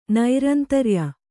♪ nairantarya